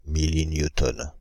Ääntäminen
France (Île-de-France): IPA: /mi.li.nju.tɔn/